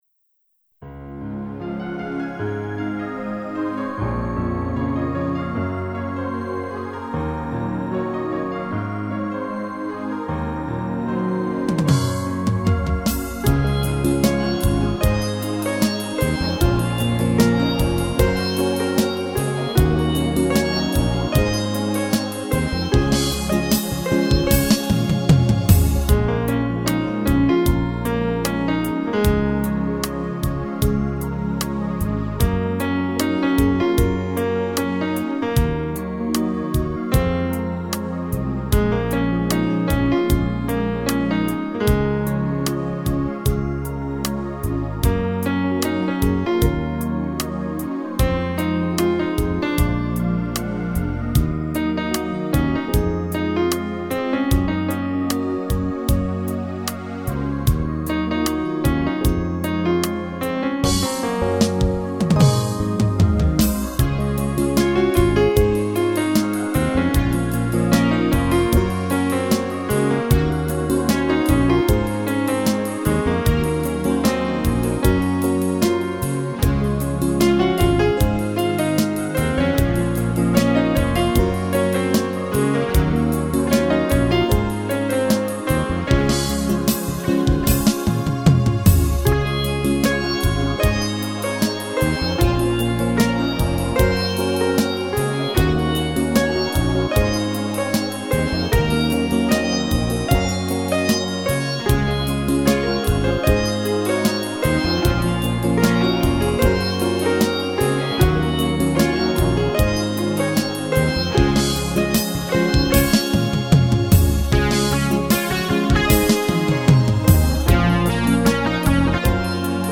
Очень красивая мелодия!